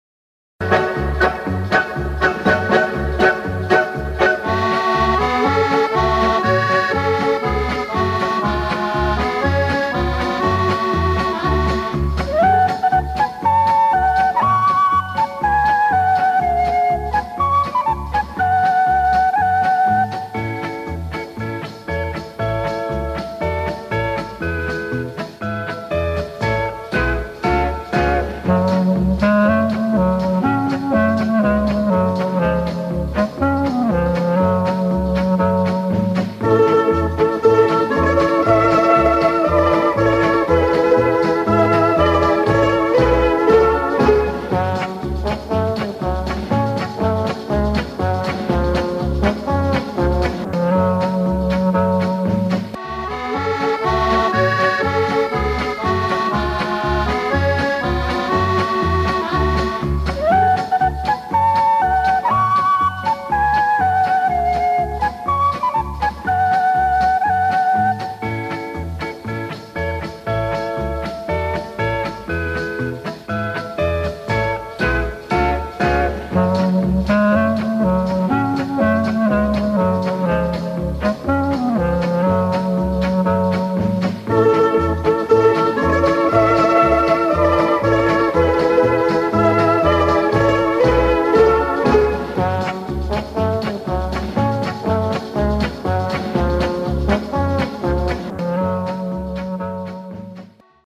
мне понравилось crying, немного подправил, правда, на скорую руку.......Ой, не ходи, Грицю тай на вечорницю  - минус